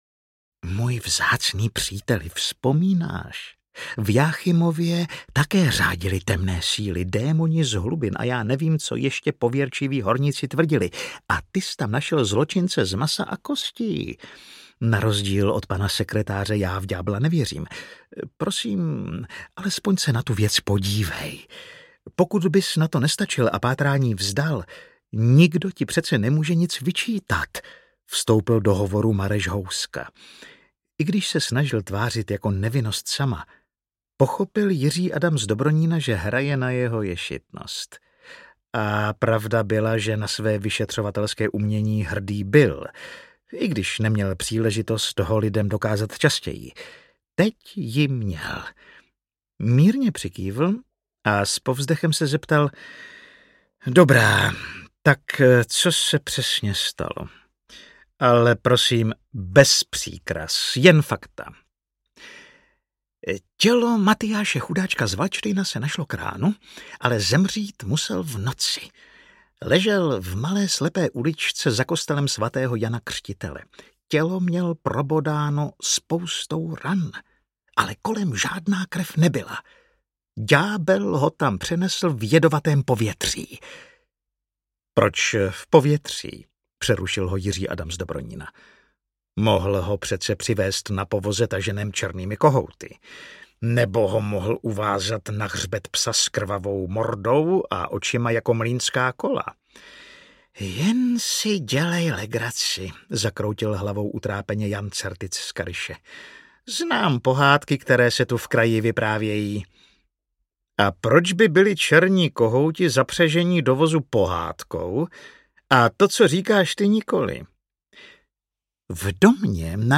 Ďáblova čísla audiokniha
Ukázka z knihy